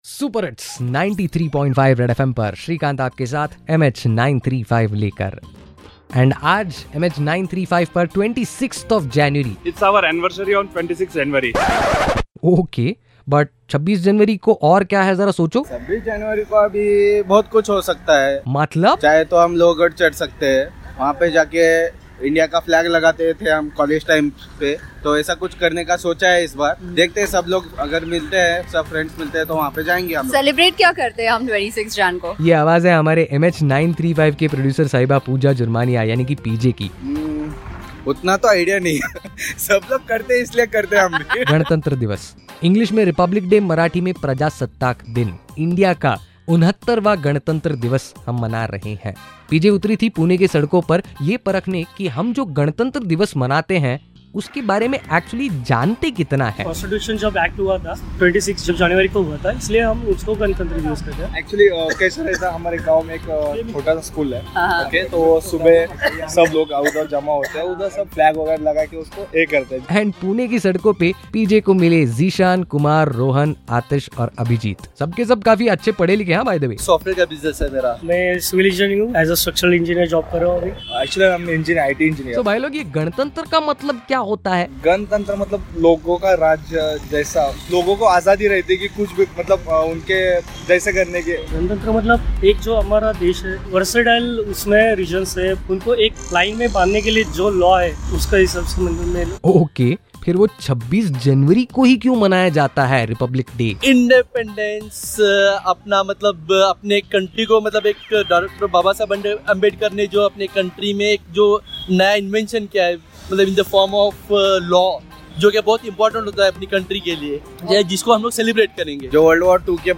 Click to find out what happened when we went on the streets to ask people about Republic Day & what is 26th Jan all about